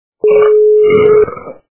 Голос - Отрыжка Звук Звуки Голос - Відрижка
» Звуки » Смешные » Голос - Отрыжка
При прослушивании Голос - Отрыжка качество понижено и присутствуют гудки.